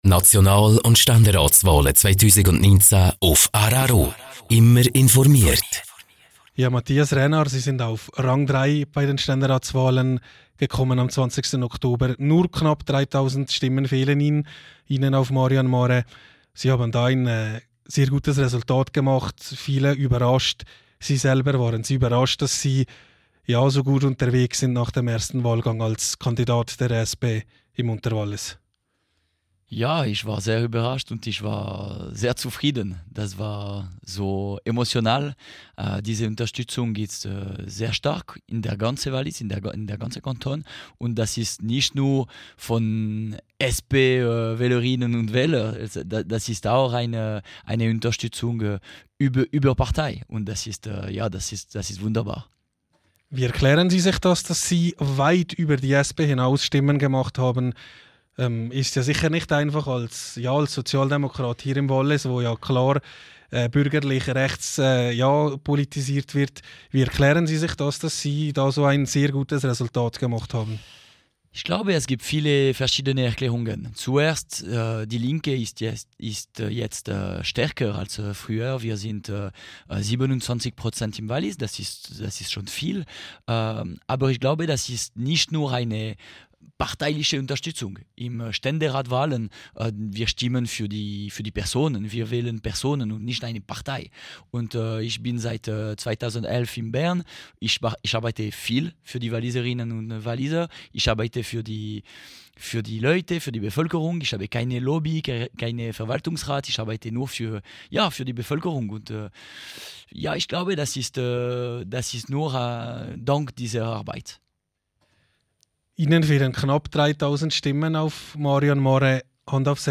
Mathias Reynard, Ständeratskandidat der SP, zu Gast bei rro: Teil 1.
SP-Ständeratskandidat Mathias Reynard im Interview - Teil 1 (Quelle: rro) SP-Ständeratskandidat Mathias Reynard im Interview - Teil 2 (Quelle: rro) SP-Ständeratskandidat Mathias Reynard im Interview - Teil 3 (Quelle: rro)